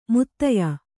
♪ muttaya